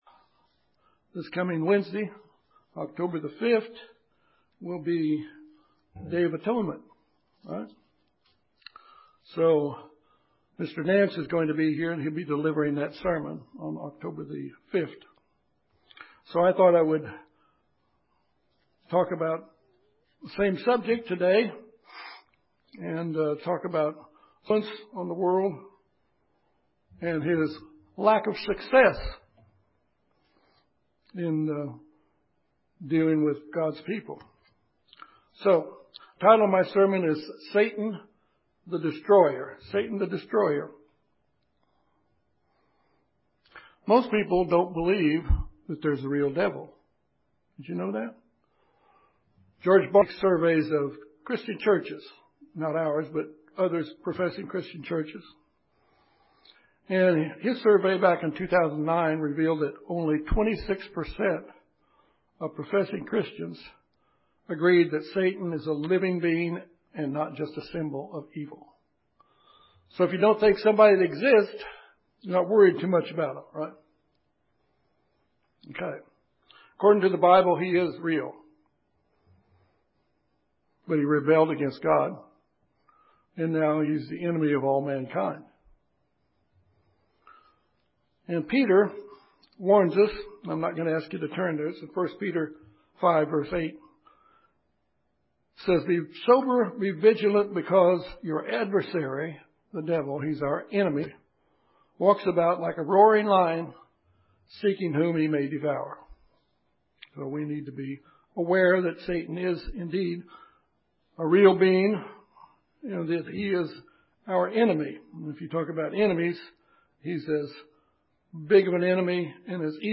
This sermon examines influence of Satan upon the world and his lack of success in dealing with God’s people.